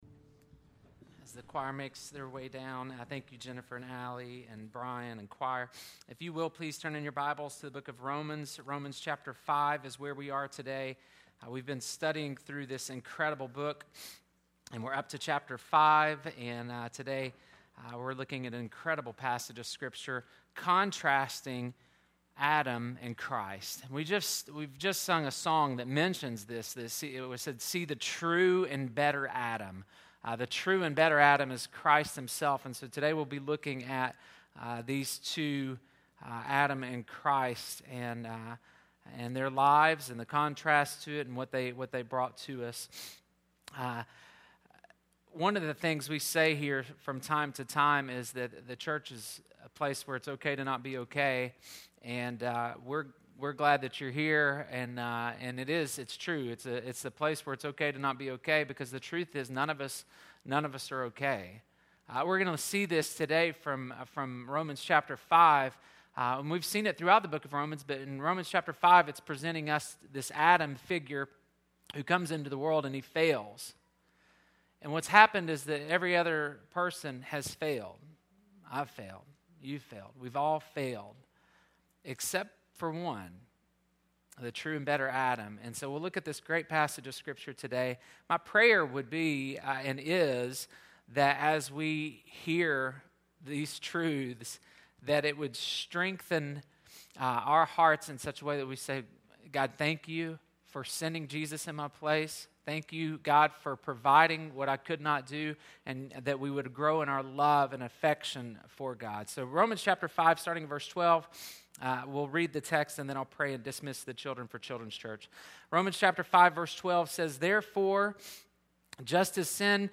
Death in Adam, Life in Christ June 23, 2019 Listen to sermon 1.